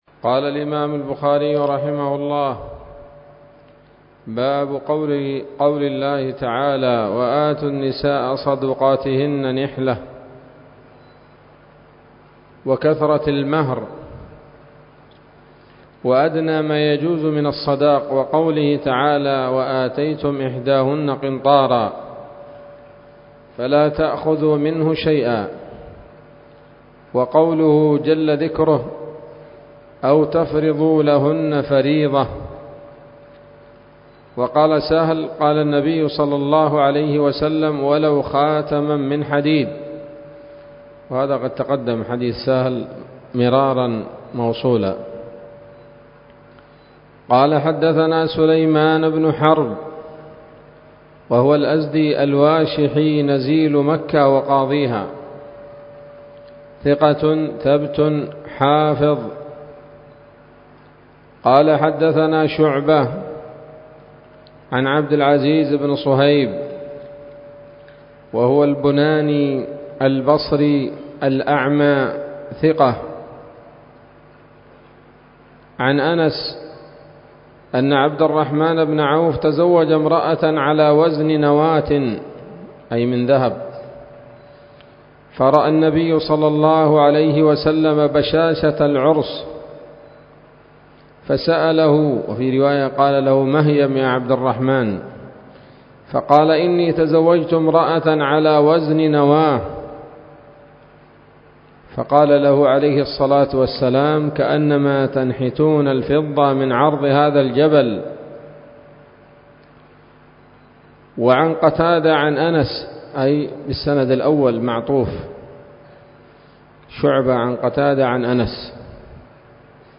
الدرس الرابع والأربعون من كتاب النكاح من صحيح الإمام البخاري